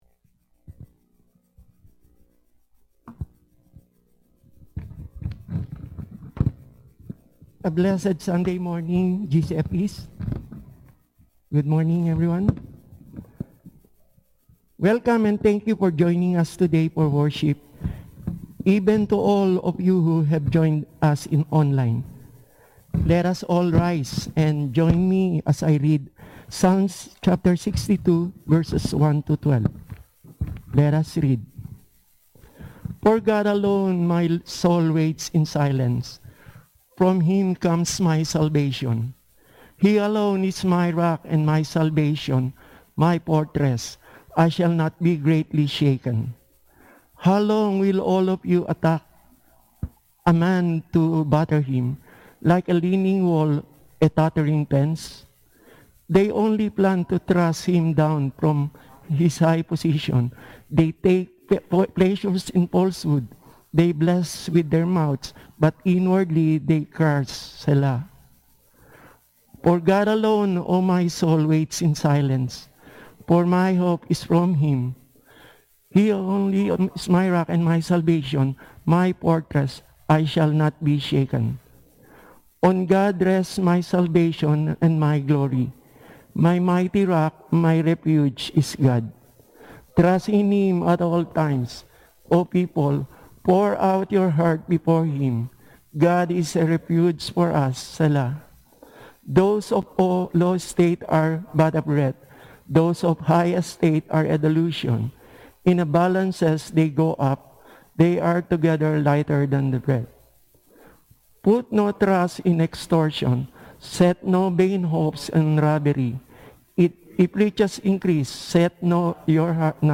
Service: Sunday